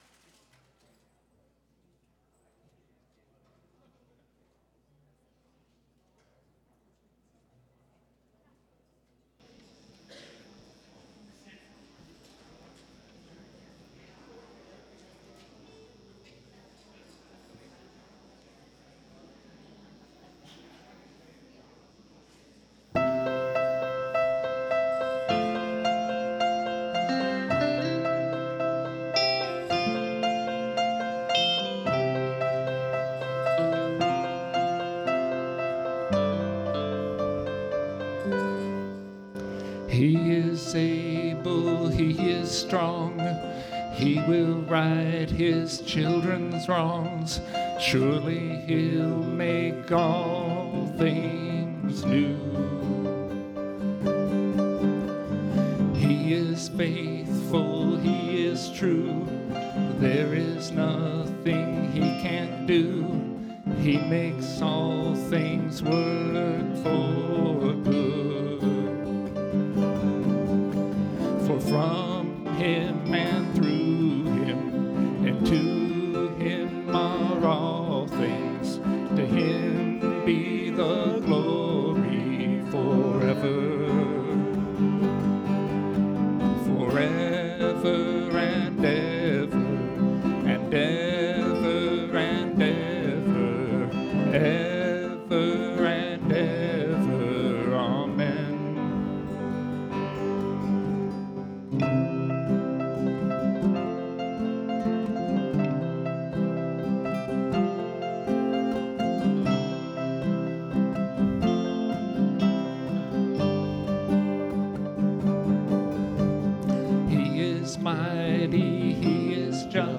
piano
bass and vocals
lead guitar